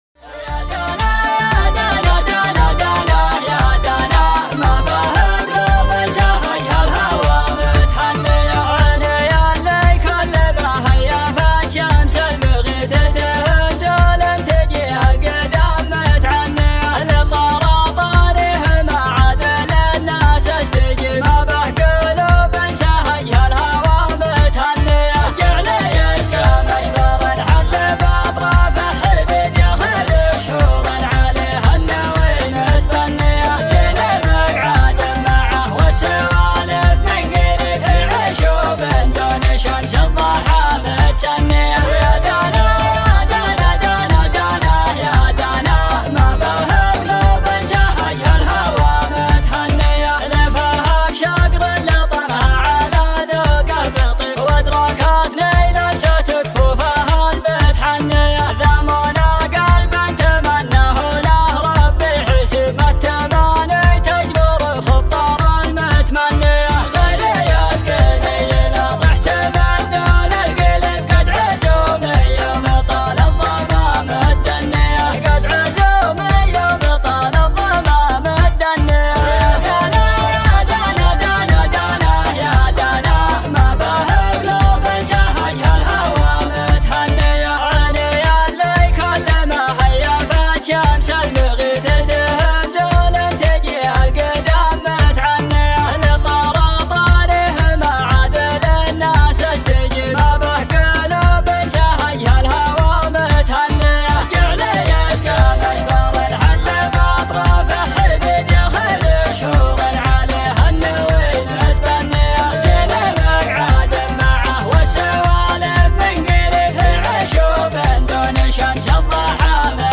شيلات طرب
شيلات مسرعة